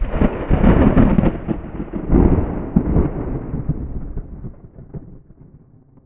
Thunder 6.wav